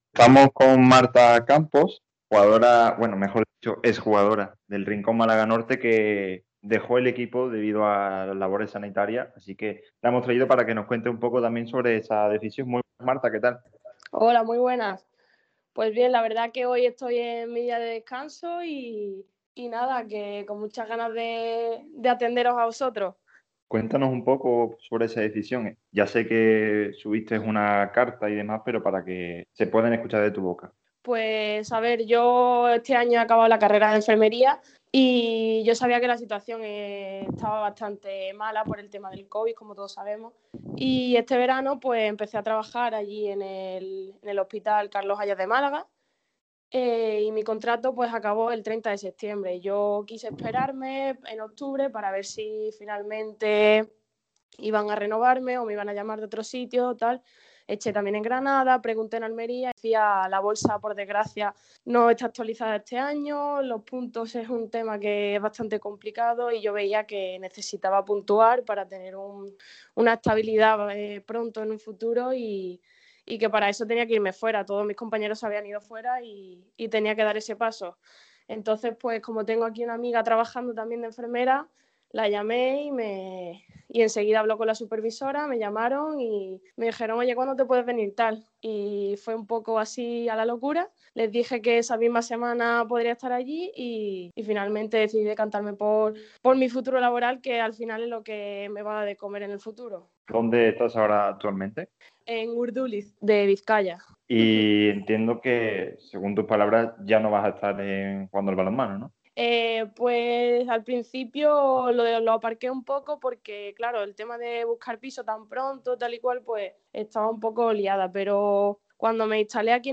Balonmano Entrevistas